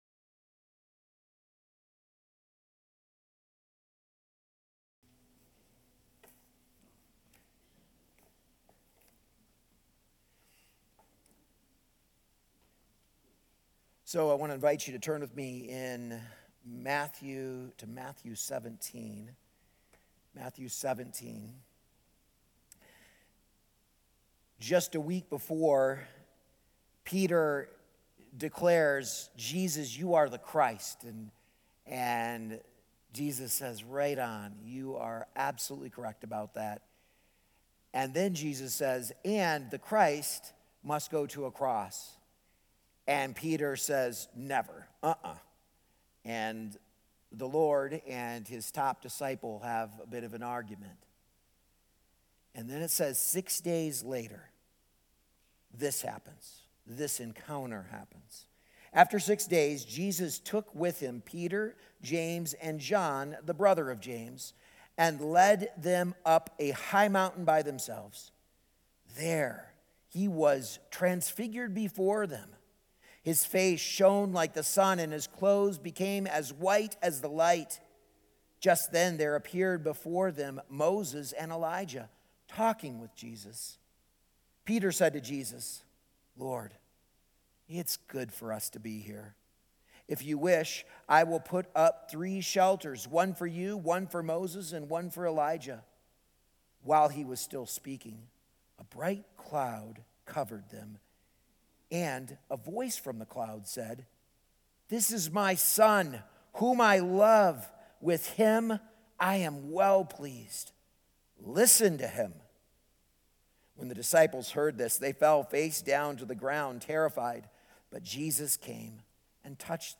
A message from the series "Encountering Christ."